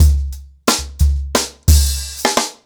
TrackBack-90BPM.21.wav